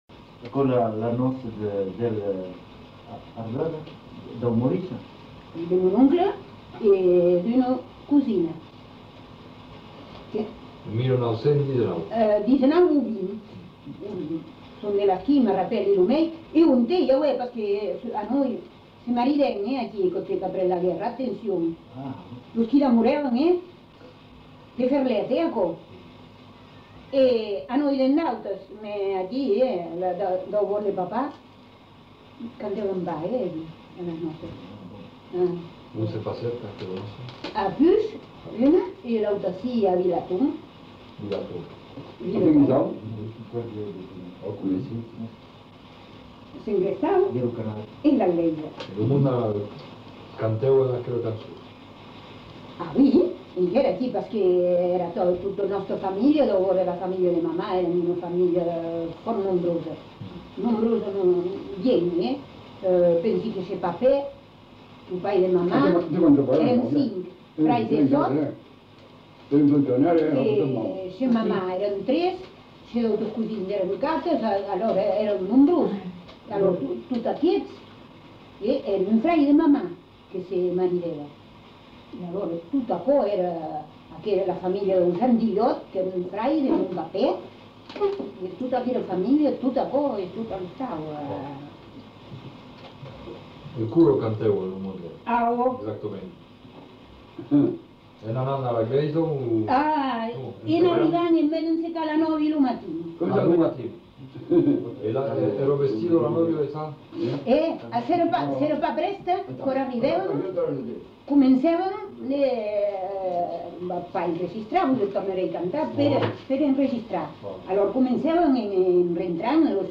Aire culturelle : Marmandais gascon
Lieu : Tonneins
Genre : témoignage thématique